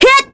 Scarlet-Vox_Attack4_kr.wav